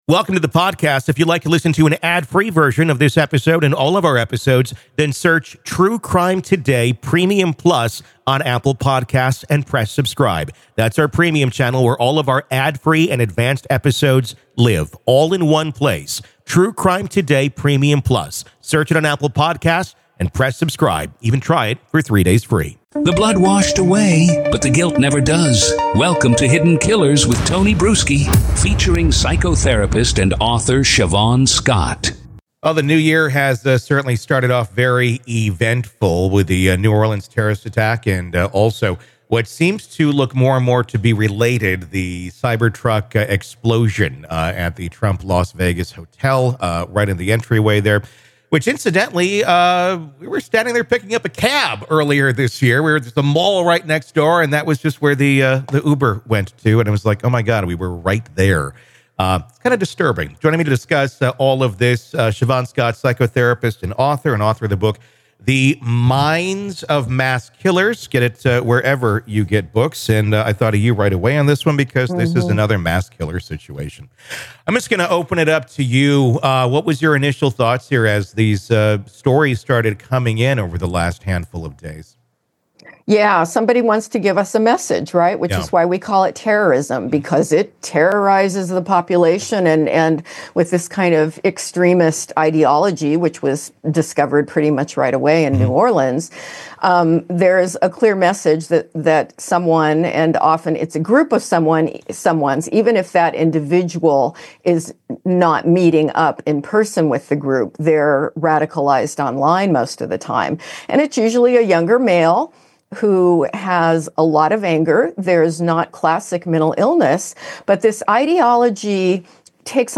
From the eerie overlap in their military service to the chilling parallels with international terrorism, this discussion sheds light on the unsettling reality of domestic radicalization.